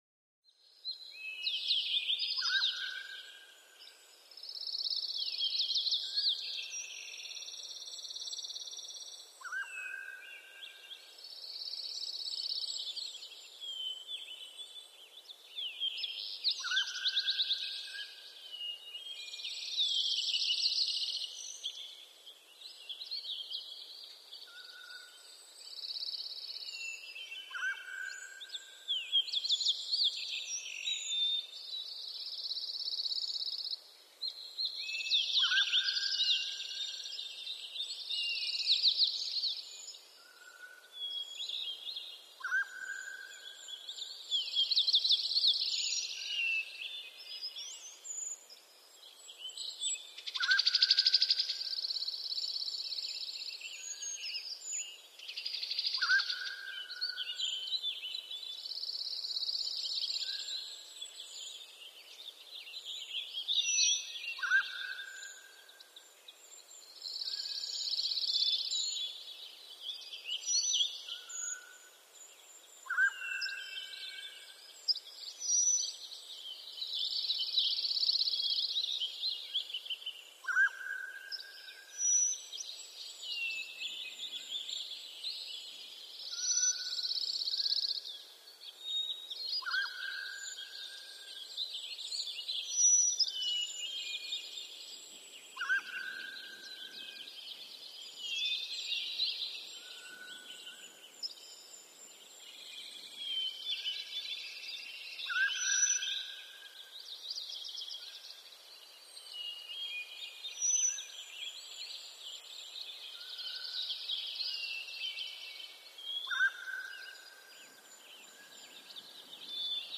Mountains
Mountain Ambience With Close Perspective Bird Song And Echoey Exotic Bird Call In The Background ( Version 2 ).